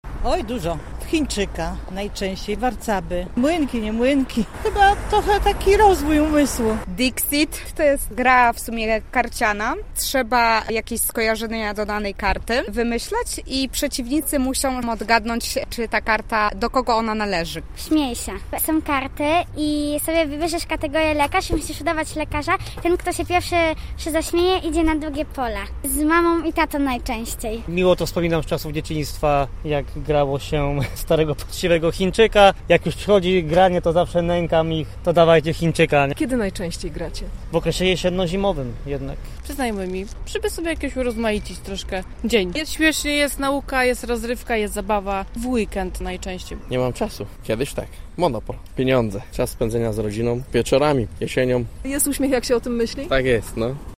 To pierwsza edycja święta, a my z pytaniem o planszówki zwróciliśmy się do zielonogórzan. Jak się okazuje mieszkańcy grają i w te starsze i nowsze gry: